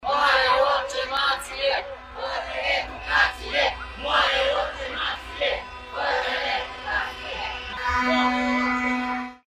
Profesorii își exprimă și în stradă nemulțumirile care vizează salarizarea din sistem: ”… fără educație, moare orice nație!”
24-mai-rdj-12-vuvuzele-protest.mp3